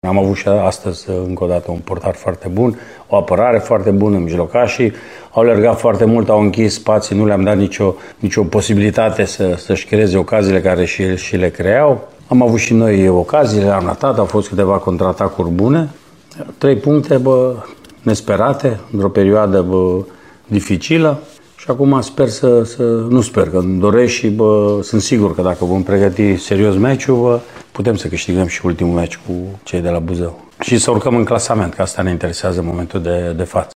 La conferința de presă de după meci, antrenorul utist Mircea Rednic a recunoscut posesia net superioară a adversarilor, dar s-a declarat bucuros că a reușit să câștige, cu execuții reușite în momentele potrivite: